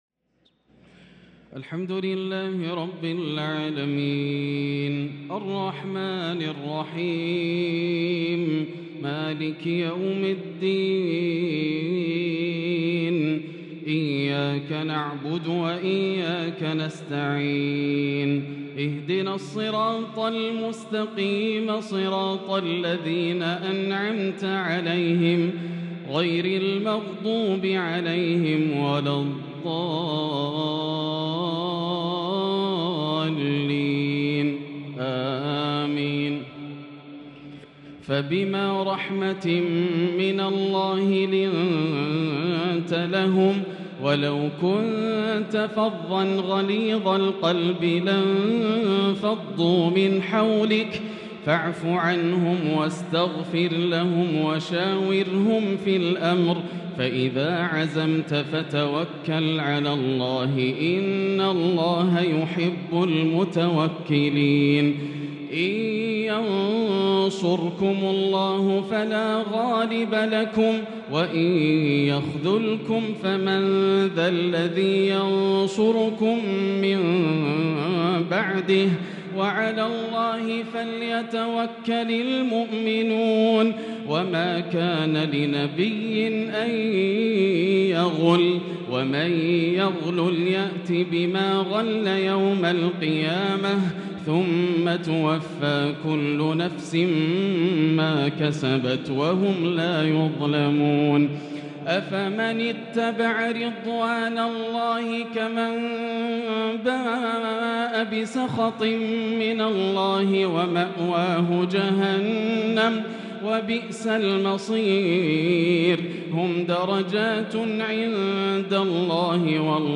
تراويح ليلة 5 رمضان 1443 من سورة آل عمران {159-200} Taraweeh 5st night Ramadan 1443H Surah Aal-i-Imraan > تراويح الحرم المكي عام 1443 🕋 > التراويح - تلاوات الحرمين